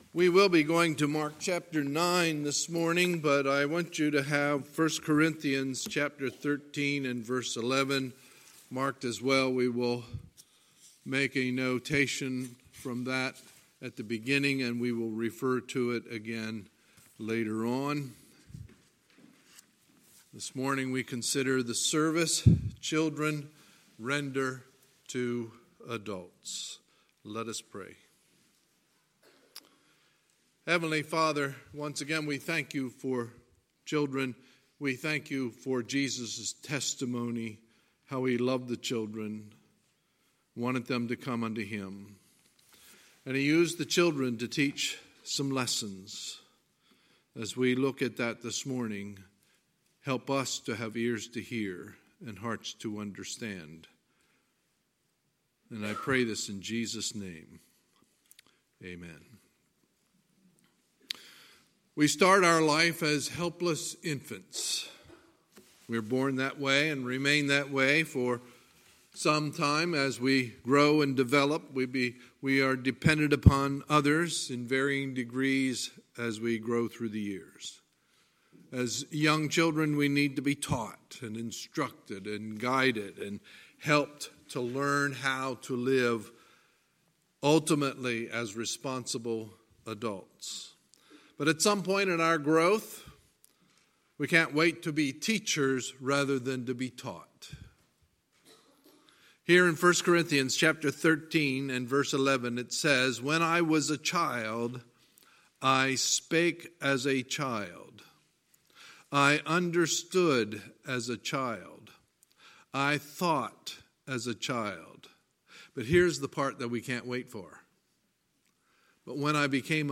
Sunday, June 9, 2019 – Sunday Morning Service